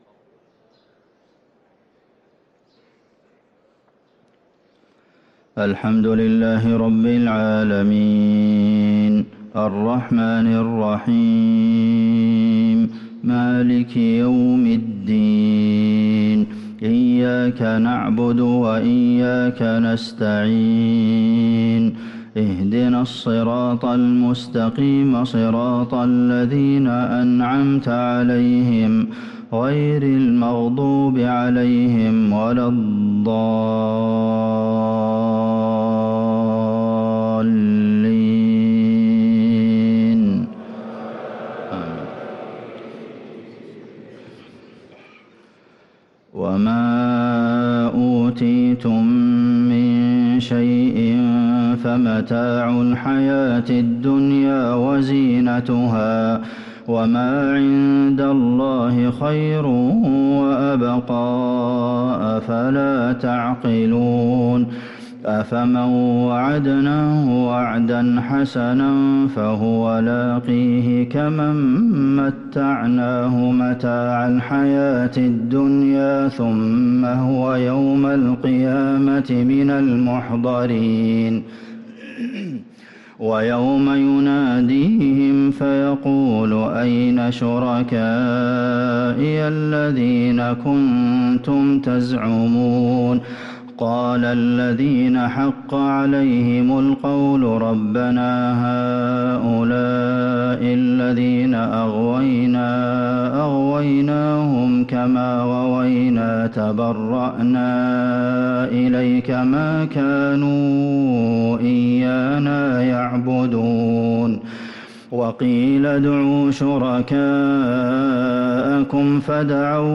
صلاة الفجر للقارئ عبدالمحسن القاسم 6 ربيع الأول 1445 هـ